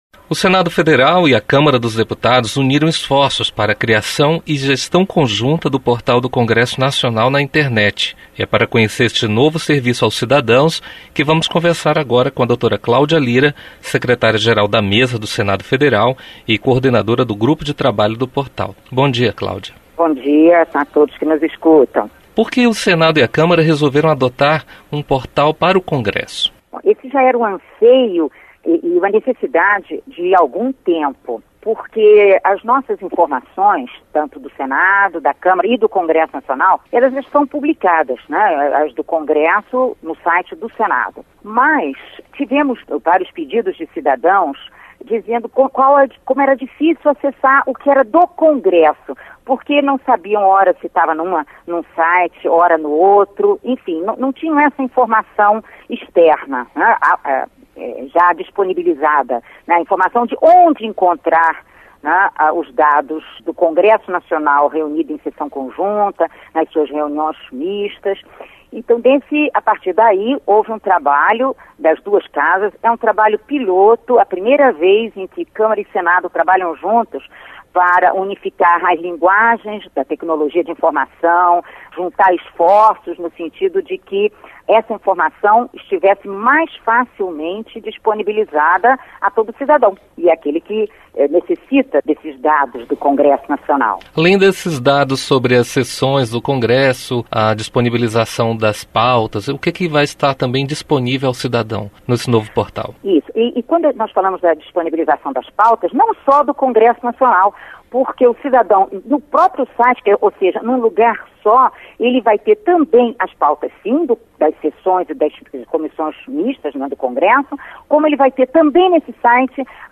Entrevista com a secretária-Geral da Mesa Diretora do Senado Federal, Cláudia Lyra.